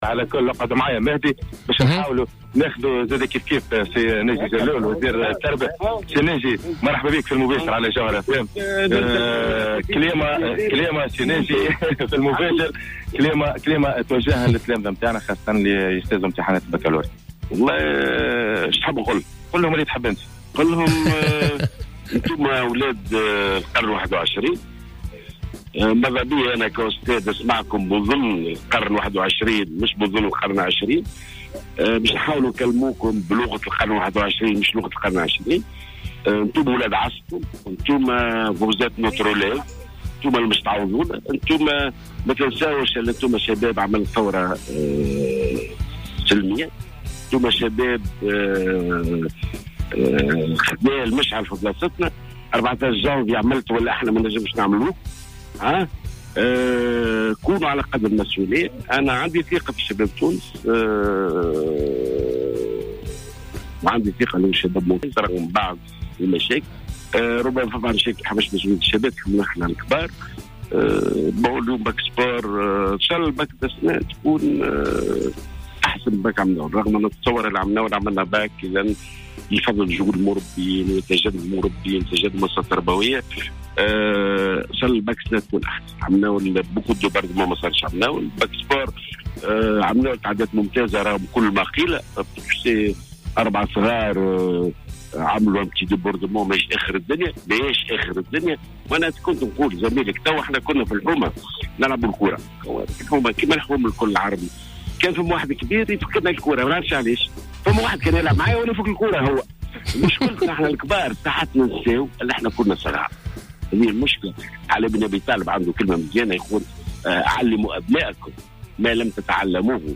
قال وزير التربية ناجي جلول في تصريح للجوهرة أف أم خلال حضوره اليوم الاثنين 18 أفريل 2016 بالملعب الأولمبي بسوسة لمتابعة سير إجراء اختبار التربية البدنية لتلاميذ الباكالوريا لهذه السنة 2016 / 2017 إن باكالوريا هذه السنة ستكون أفضل من التي مضت.